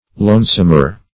Lonesome \Lone"some\, a. [Compar. Lonesomer; superl.